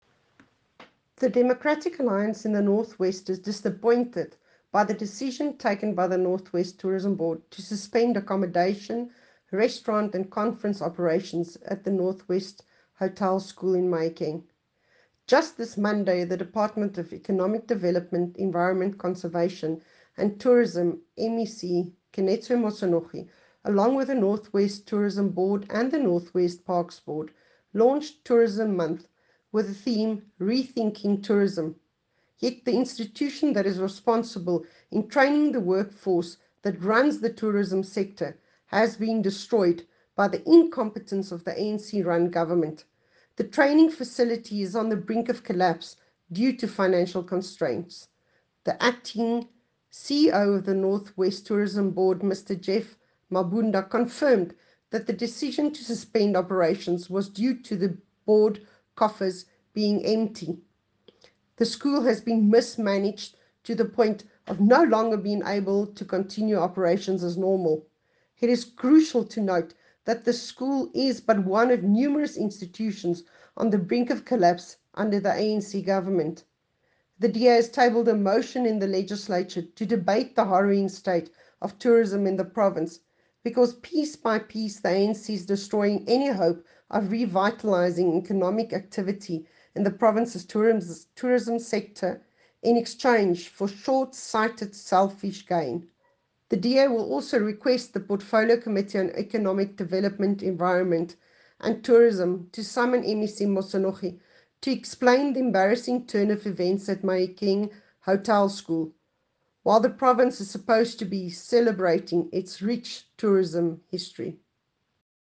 Note to Editors: Please find the attached soundbite in